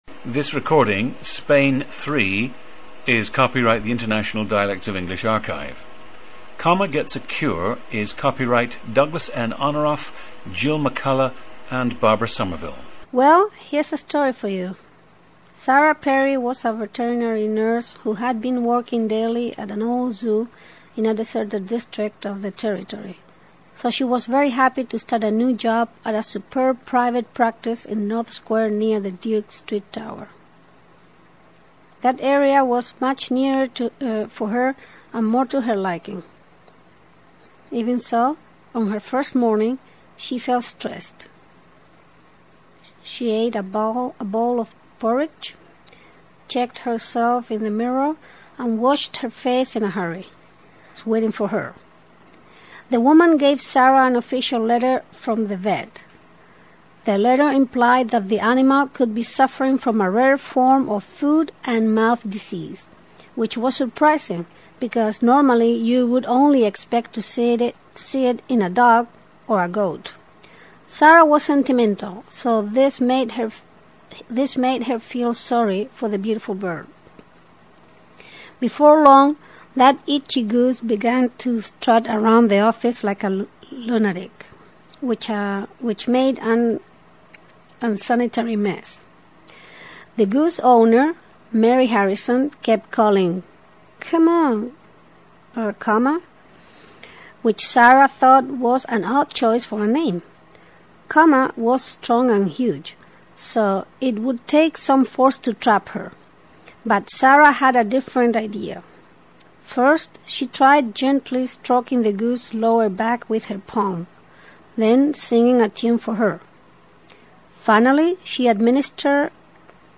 PLACE OF BIRTH: Seville, Spain
GENDER: female
Subject is a native Andalusian speaker.
The recordings average four minutes in length and feature both the reading of one of two standard passages, and some unscripted speech.